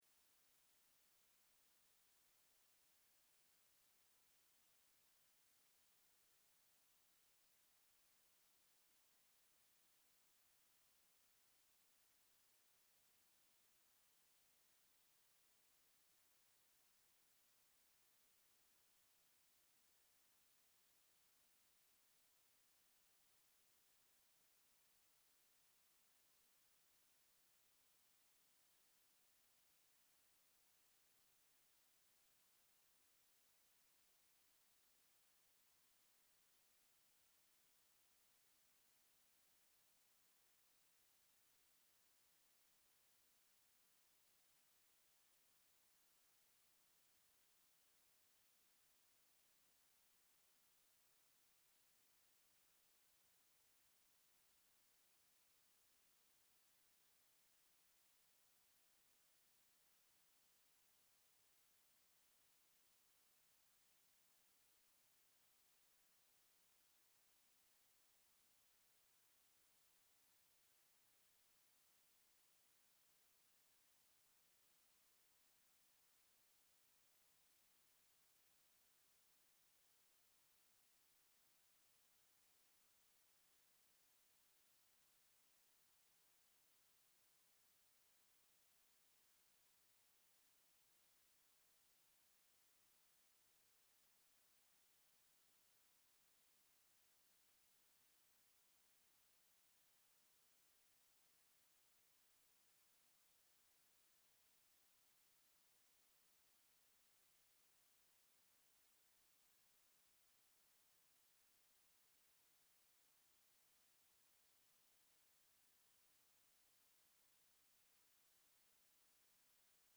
Sermon March 16, 2025